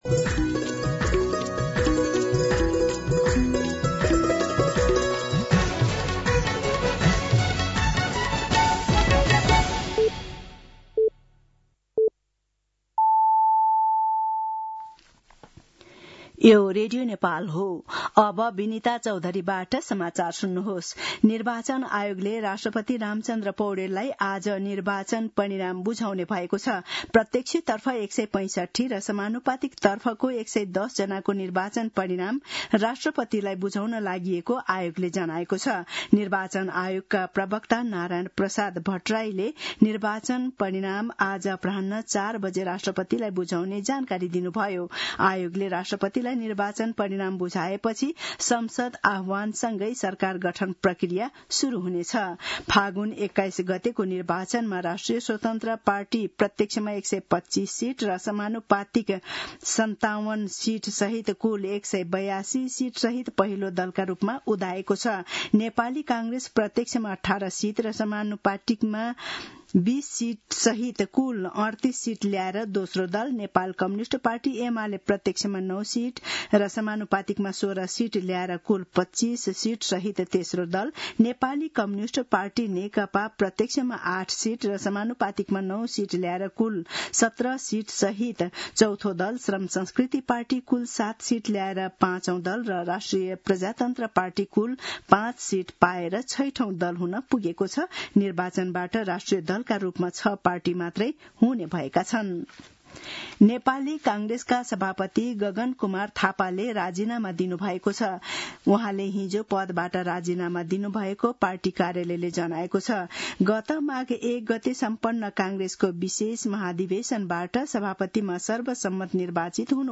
दिउँसो १ बजेको नेपाली समाचार : ५ चैत , २०८२